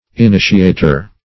Initiator \In*i"ti*a`tor\, n. [L.]